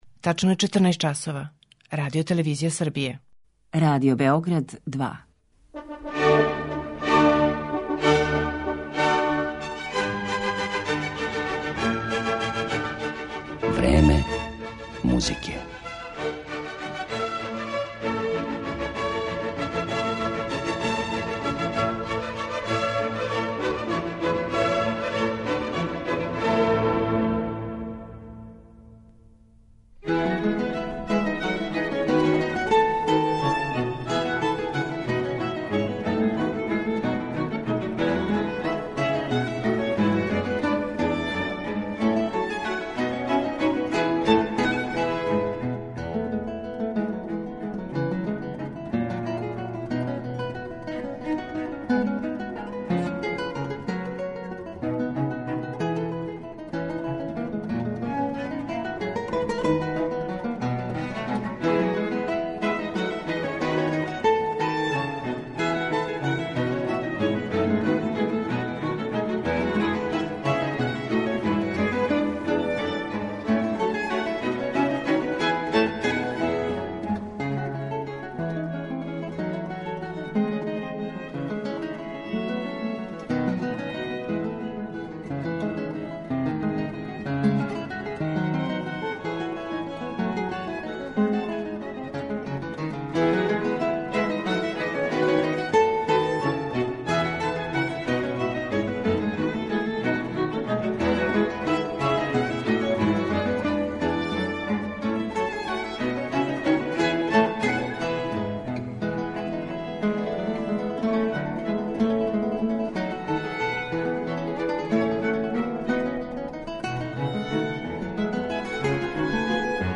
кинеска гитаристкиња